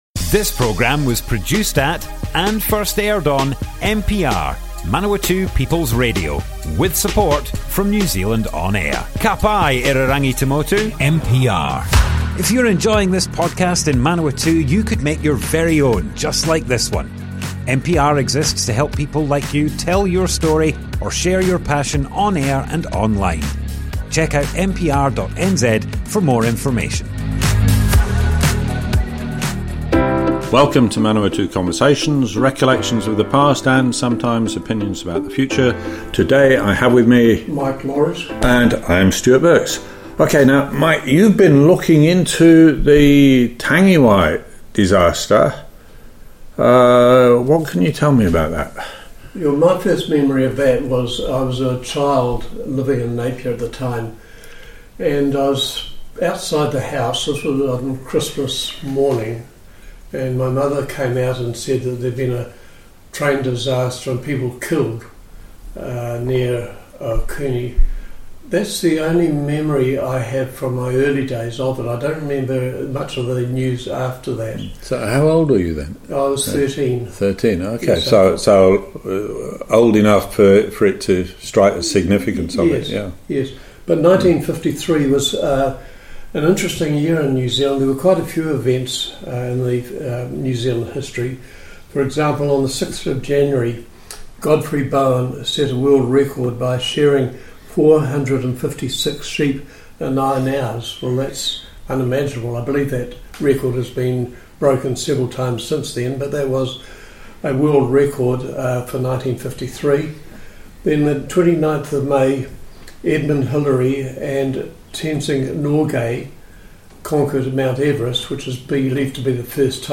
Manawatu Conversations More Info → Description Broadcast on Manawatu People's Radio, 3rd February 2026.
oral history